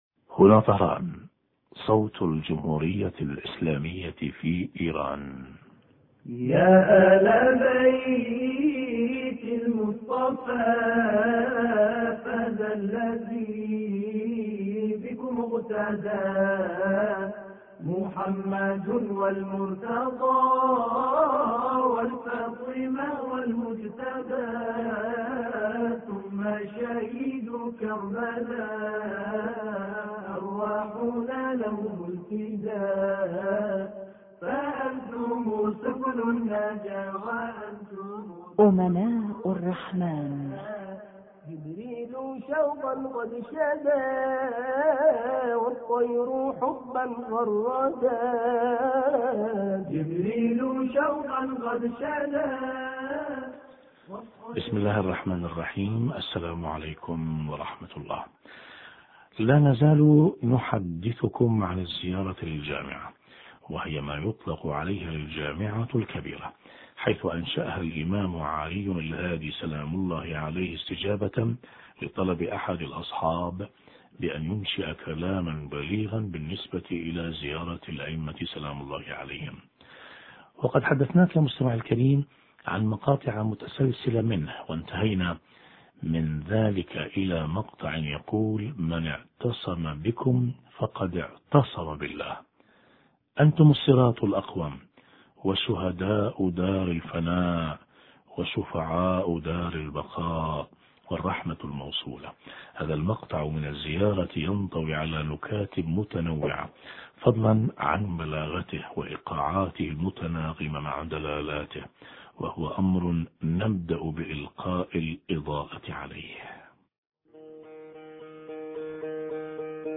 معنى الاعتصام بالائمة(ع) الذي هو مظهر الاعتصام بهم ومعنى كونه صراطه الاقوم حوار